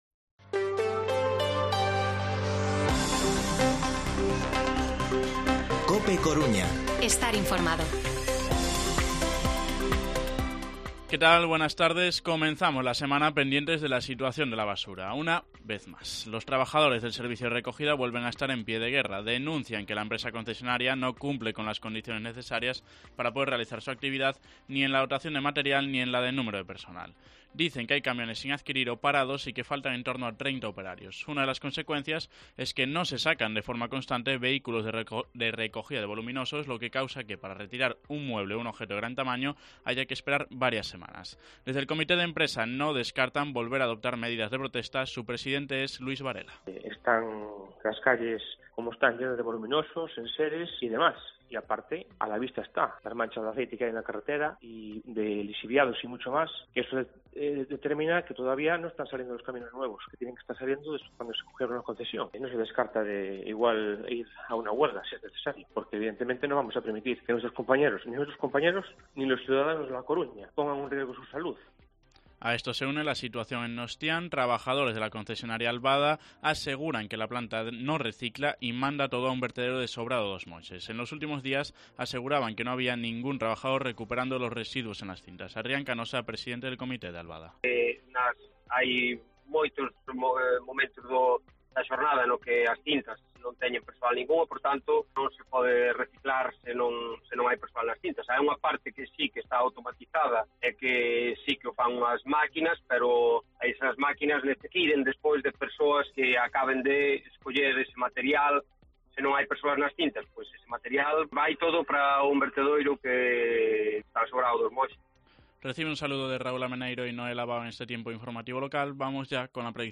Informativo Mediodía COPE Coruña lunes, 22 de agosto de 2022 14:20-14:30